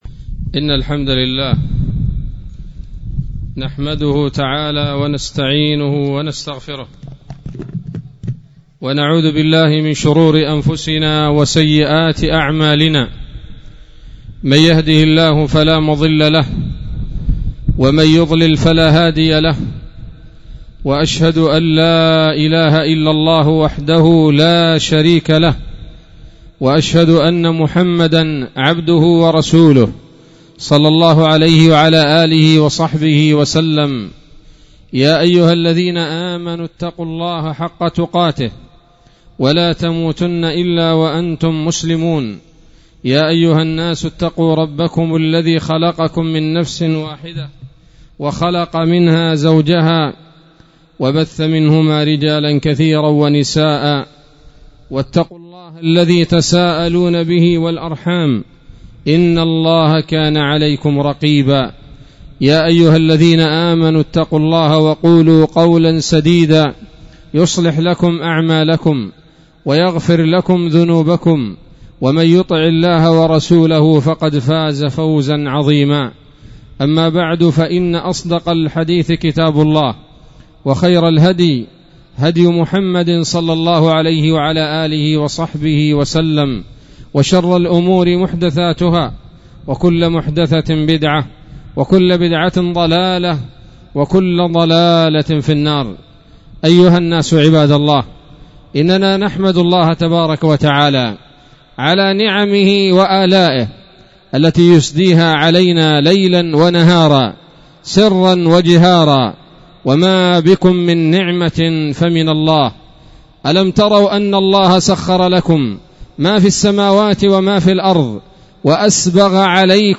خطبة-عيد-الفطر-المبارك-1444-هـ-_-دقة-عادية.mp3